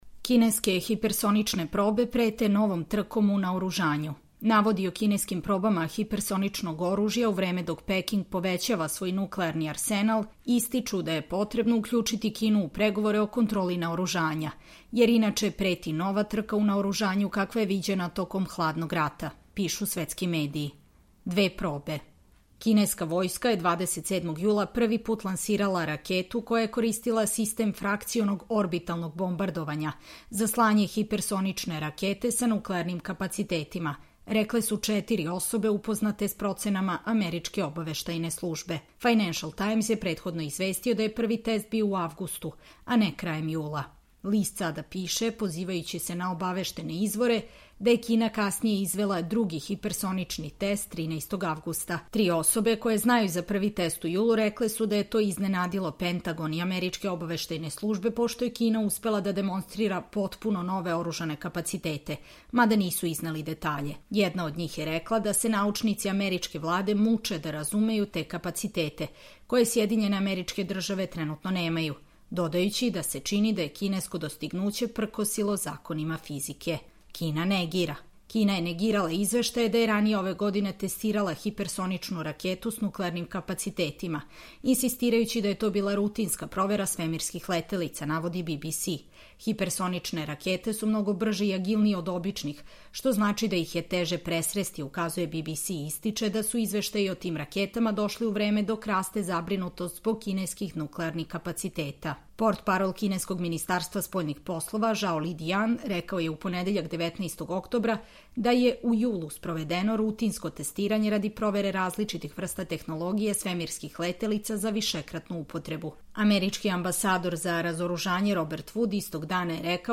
Čitamo vam: Kineske hipersonične probe prete novom trkom u naoružanju